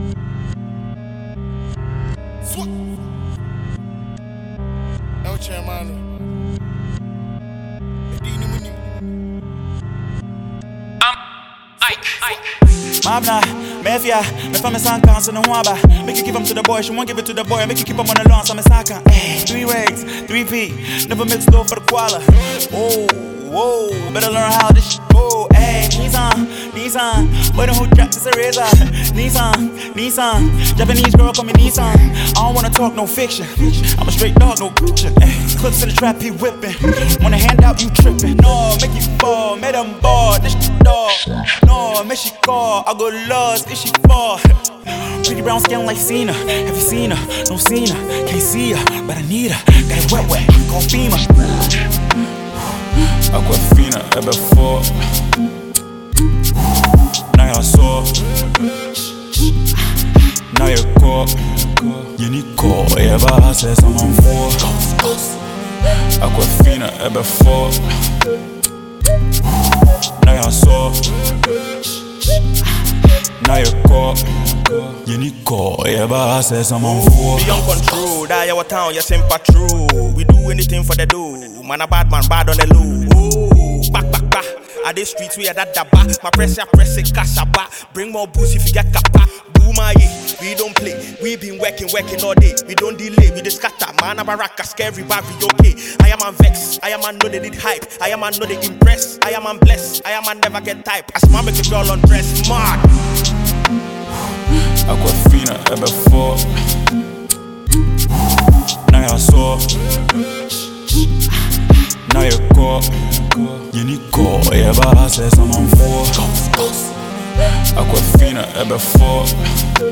hiphop tune